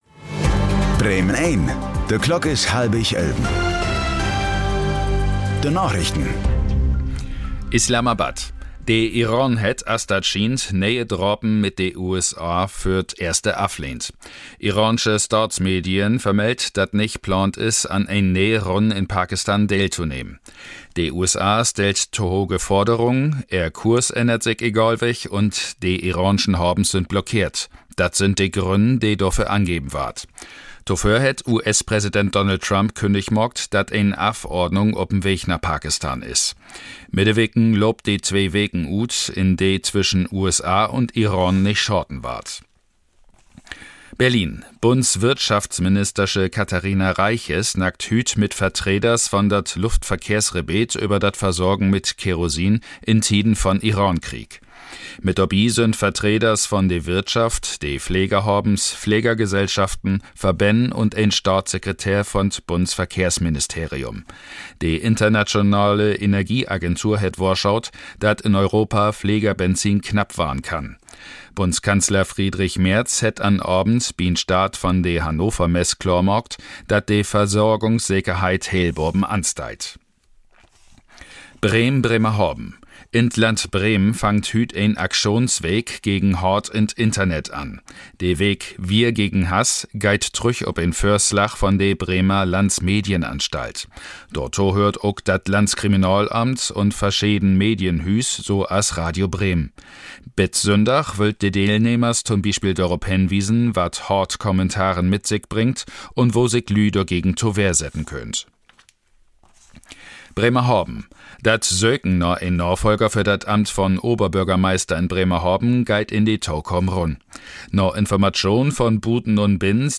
Plattdüütsche Narichten vun'n 20. April 2026